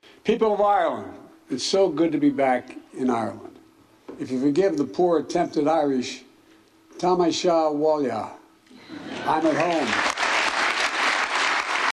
Speaking in the Dáil Chamber, President Biden used a cúpla focal to start his speech: